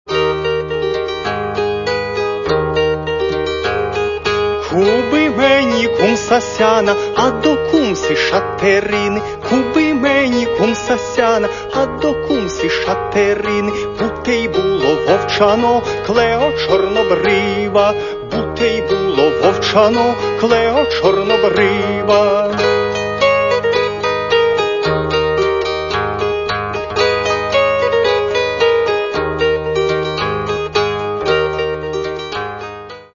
Каталог -> Народна -> Бандура, кобза тощо
Mp3Танець (з дідівською приспівкою)